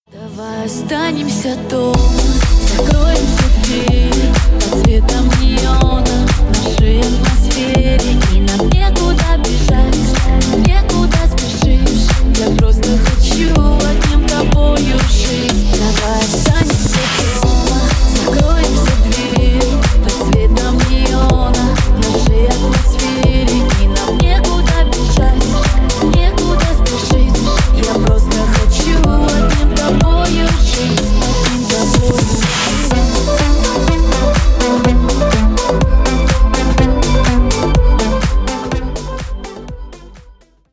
Ремикс # Поп Музыка